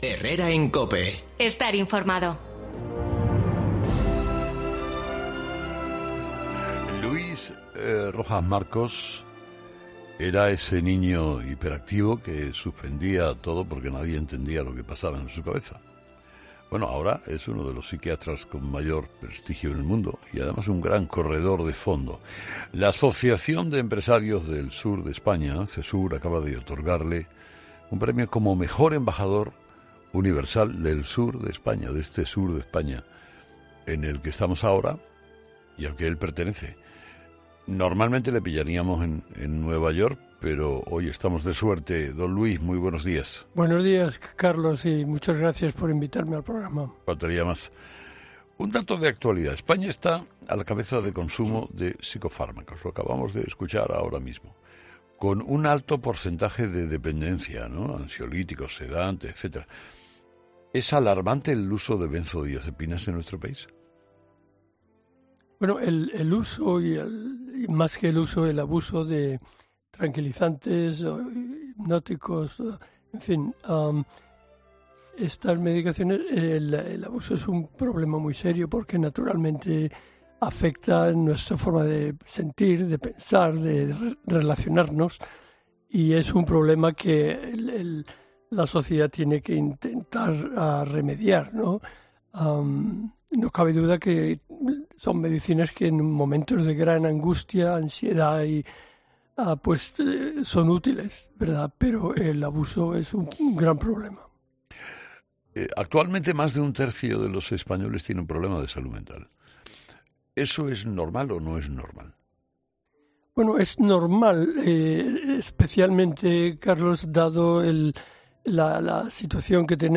Por esta razón, Carlos Herrera ha decidido comenzar su charla con el experto hablando del excesivo uso de medicamentos , en especial de la benzodiacepina , algo que preocupa al médico :